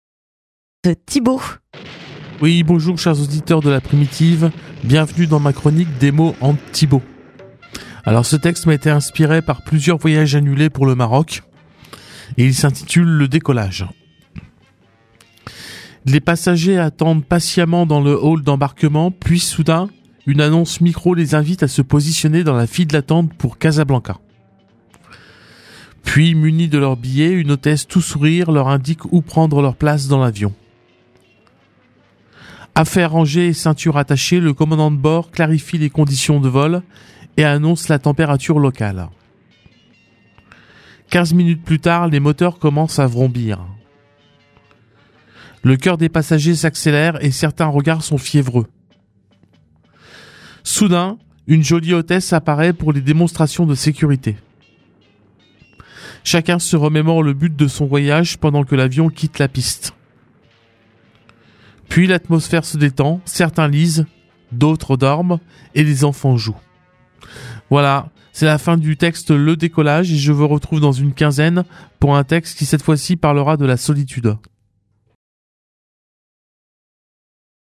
Chronique du 22 mai
15 minutes plutard les moteurs commencent à vrombir...